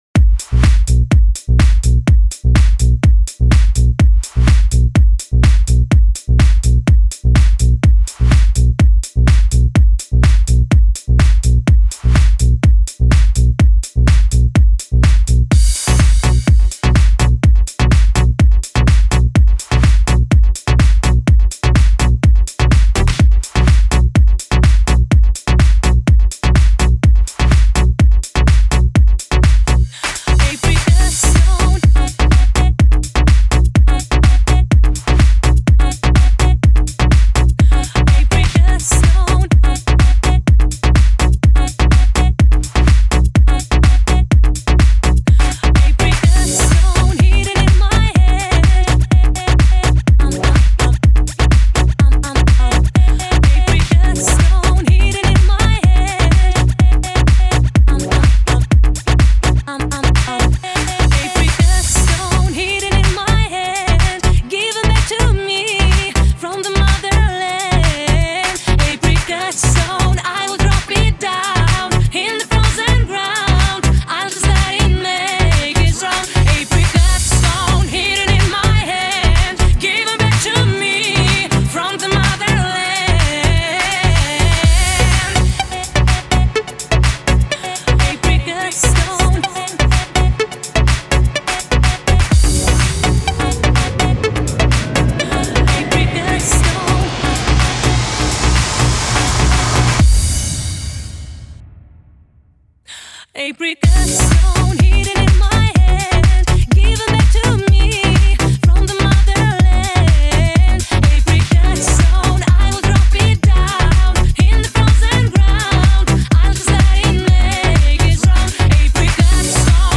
Жанр: Dance music